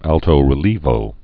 (ăltō-rĭ-lēvō, ältō-rēl-yāvō)